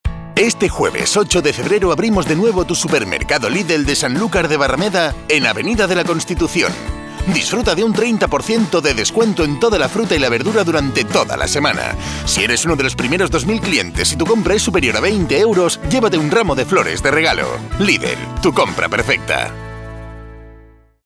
kastilisch
Sprechprobe: Werbung (Muttersprache):
Voice talent specialized in Dubbing Movies, Documentaries & Cartoons